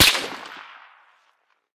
heav_crack_01.ogg